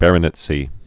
(bărə-nĭt-sē, -nĕtsē)